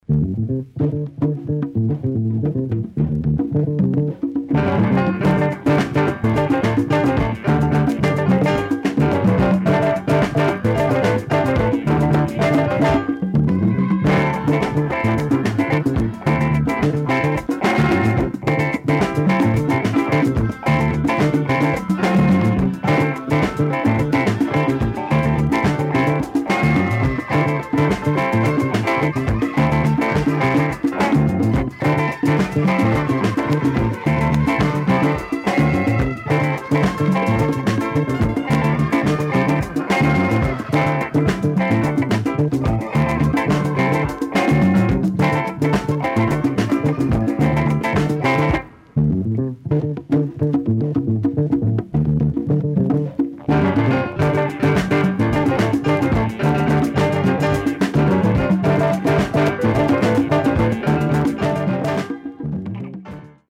tight instrumental jazzy funk cut